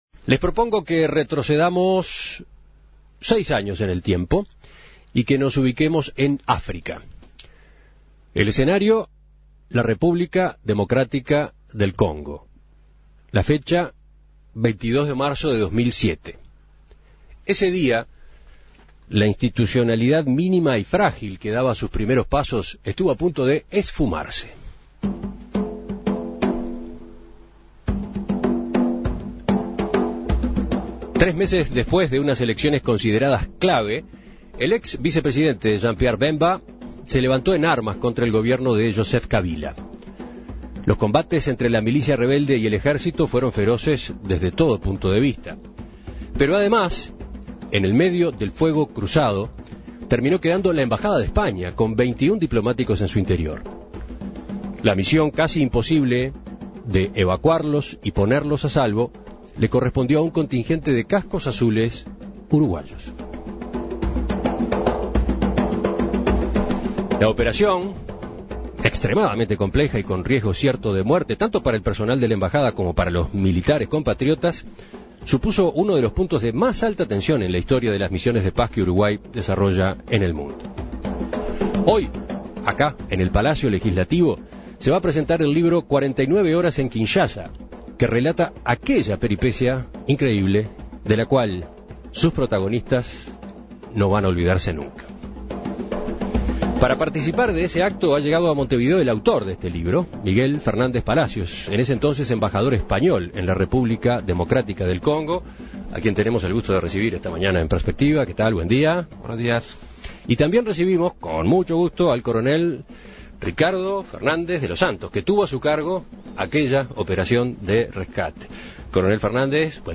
Entrevistas